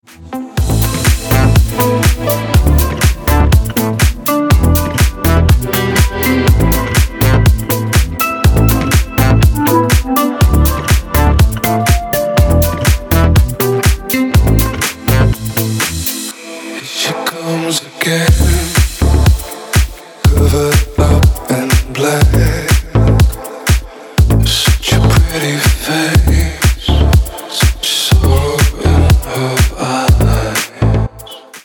мужской голос
remix
deep house
Electronic
EDM
Стиль: deep house